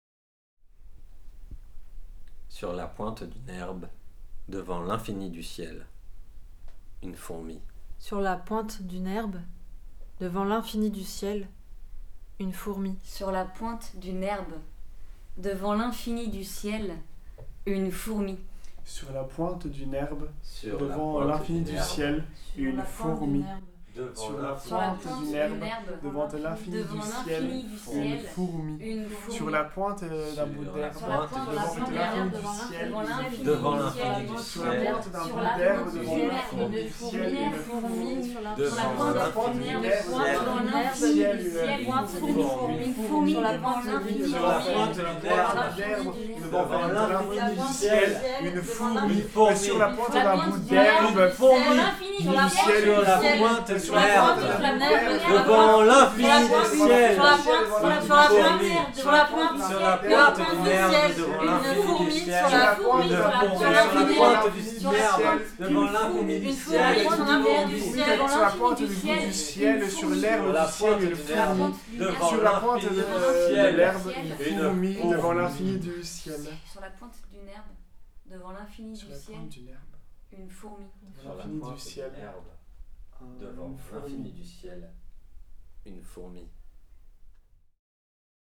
Les verres titillent au loin.
Les artistes se placent aux quatre coins du groupe et le dernier haïku est récité en canon.
La musique s’arrête. Les mains applaudissent.
CHORALE